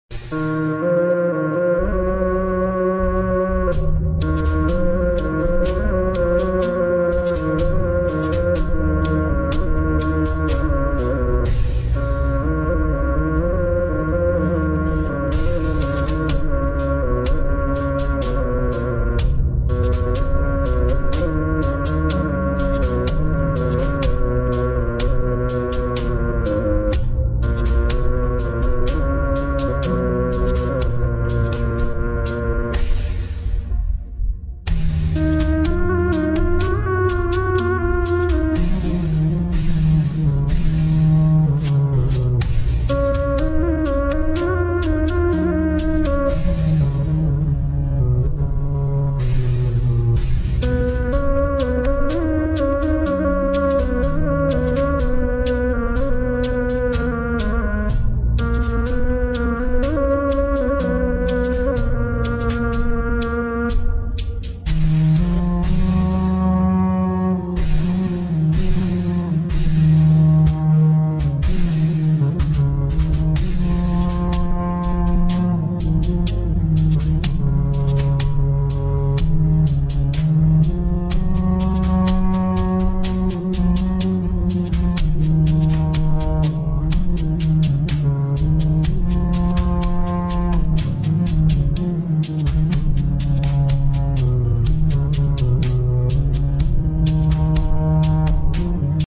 𝑆𝐴𝐷 𝐵𝐴𝐶𝑅𝑂𝑈𝑁𝐷 𝐴𝑅𝐴𝐵𝐼𝐶 𝑀𝑈𝑆𝐼𝐶 [𝑆𝐿𝑂𝑊𝐸𝐷]+𝑅𝐸𝑉𝐸𝑅𝐵 𝑨𝑵𝑫 𝑩𝑨𝑨𝑺 𝑩𝑶𝑺𝑻𝑬𝑫